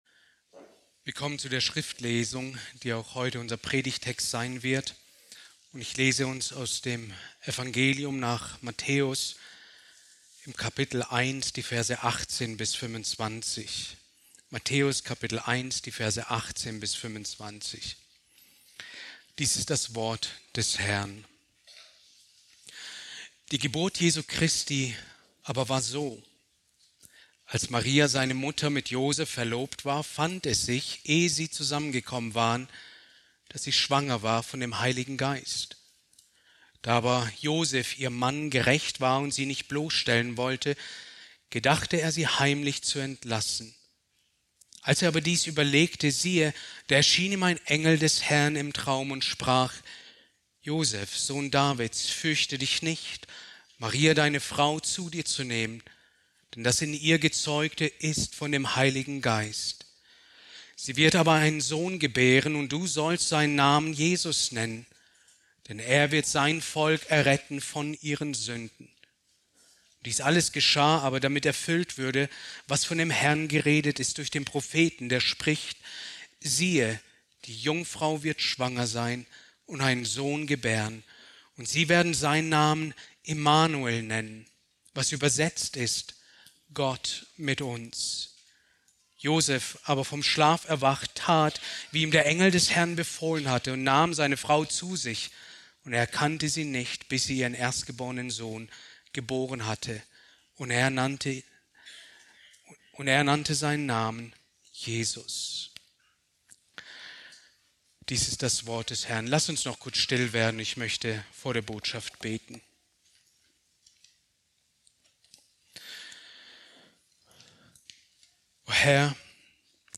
Predigt aus der Serie: "Christologie"